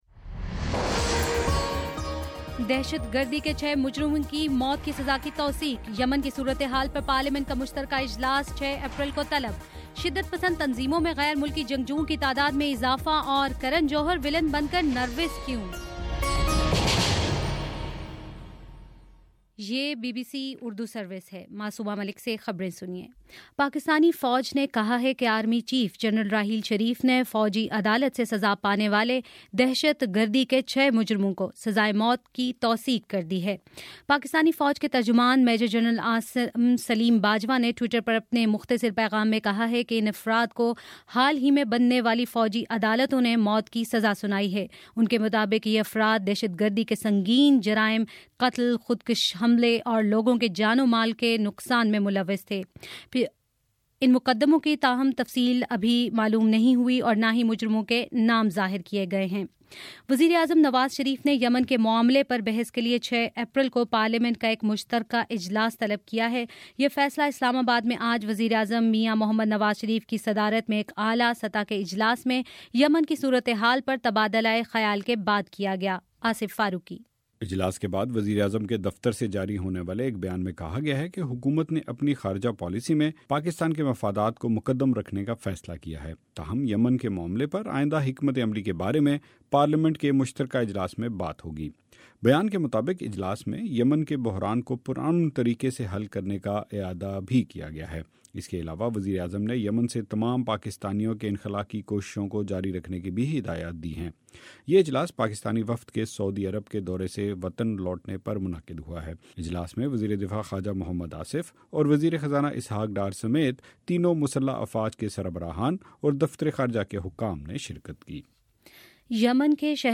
اپریل 2 : شام چھ بجے کا نیوز بُلیٹن